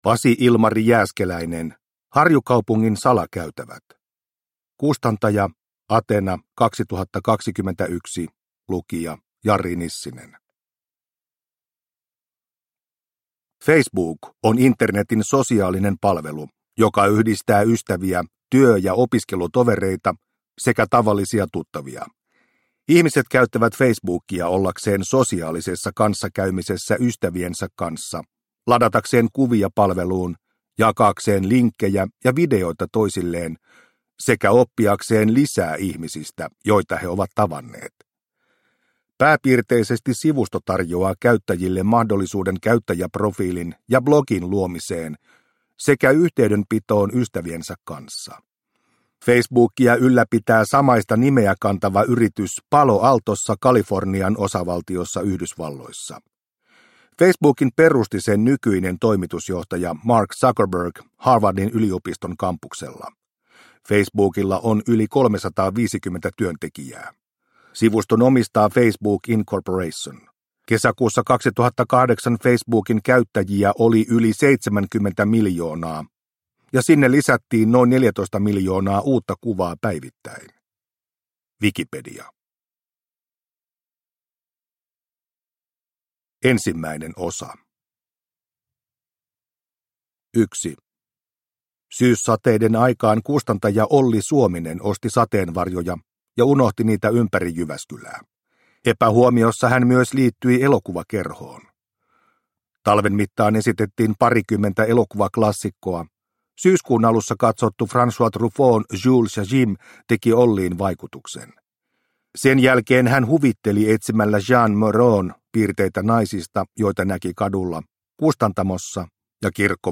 Harjukaupungin salakäytävät – Ljudbok – Laddas ner